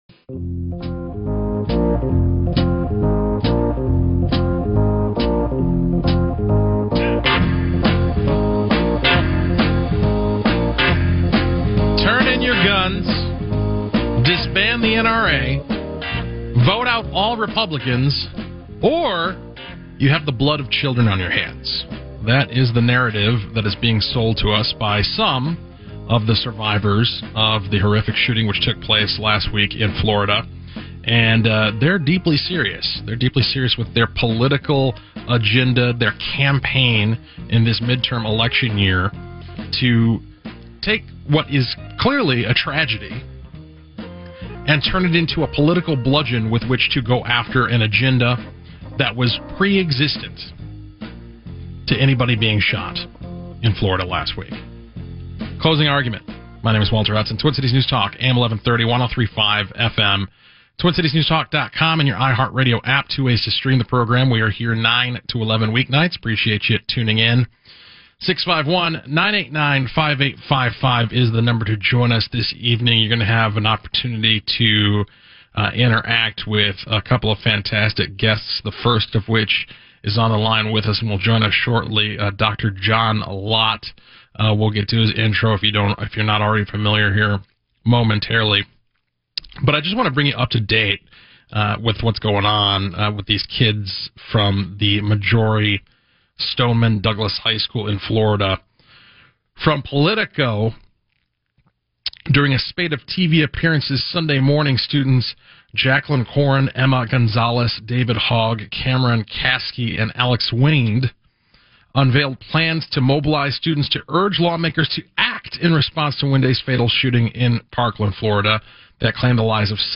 Dr. John Lott was on the giant 50,000-watt KTLK’ “Closing Argument” to discuss how gun control advocates keep pushing regulations that would do nothing beneficial to stop mass public shootings.